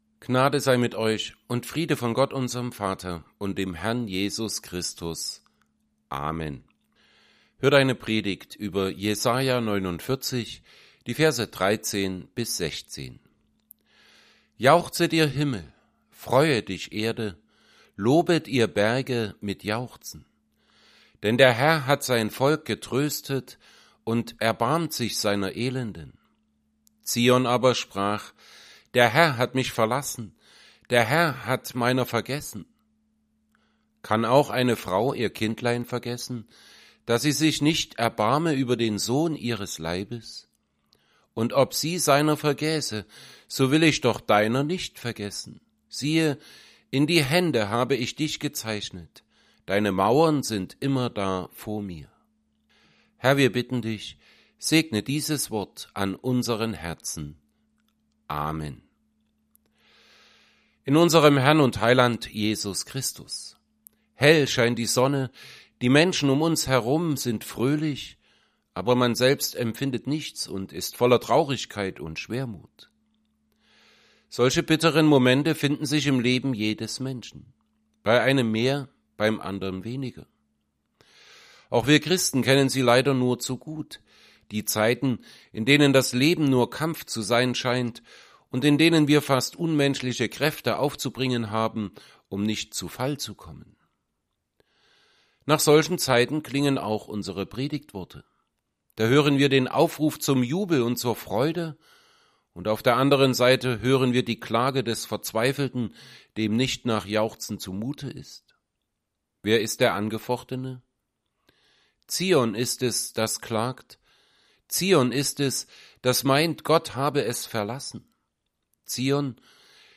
Altes Testament Passage: Isaiah 49:13-16 Gottesdienst: Gottesdienst %todo_render% Dateien zum Herunterladen Notizen « 7.